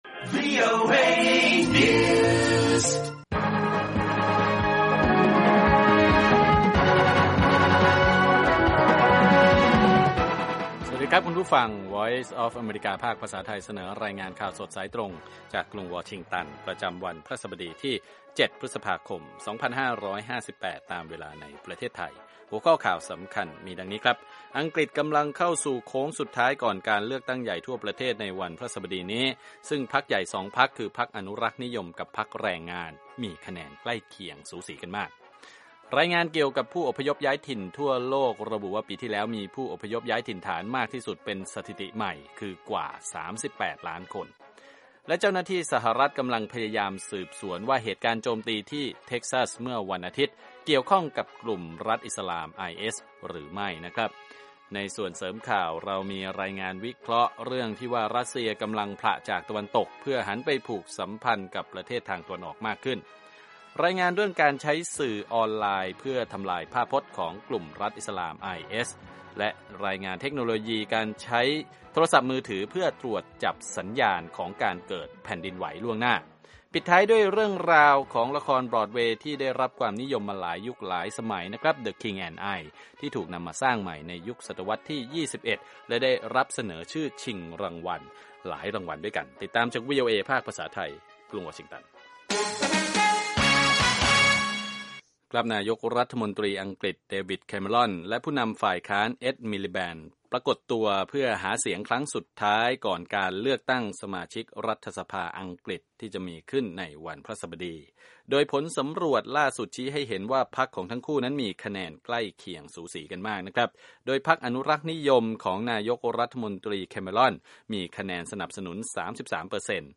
ข่าวสดสายตรงจากวีโอเอ ภาคภาษาไทย 8:30–9:00 น. พฤหัสบดีที่ 7 พฤษภาคม 2558